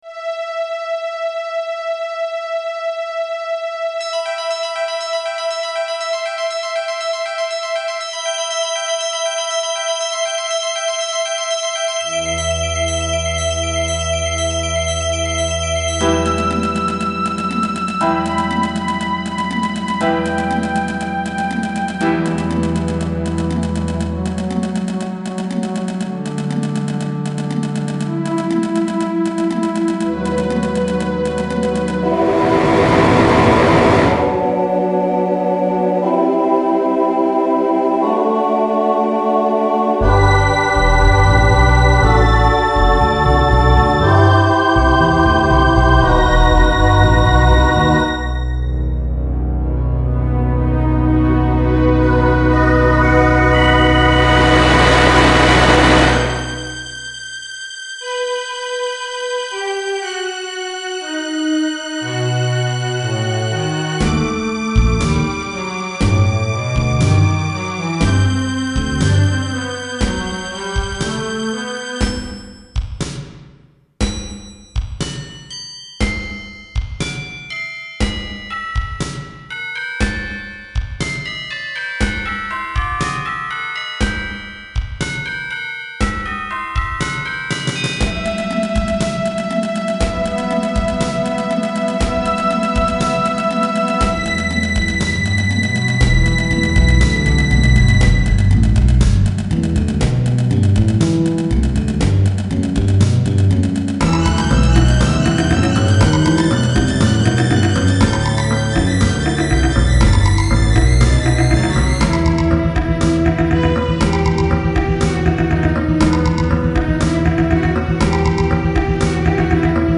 MIDとGuitarの曲